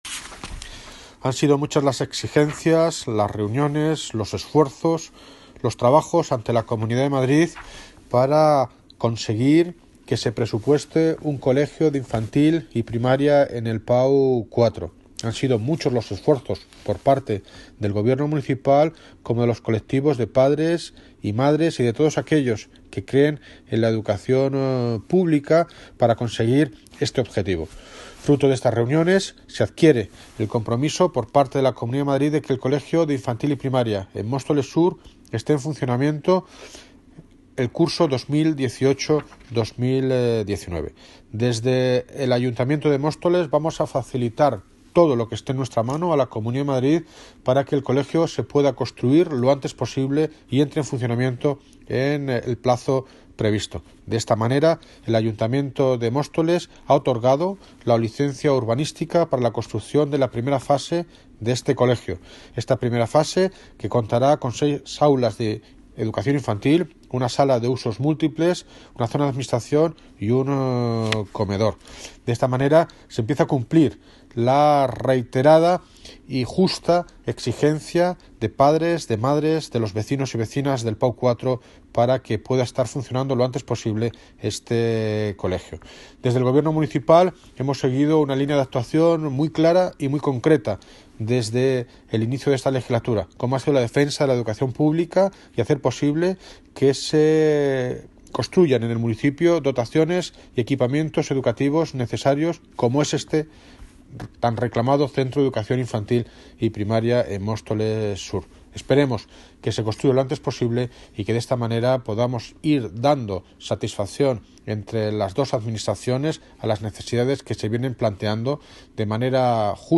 Audio - David Lucas (Alcalde de Móstoles) Sobre Colegio PAU 4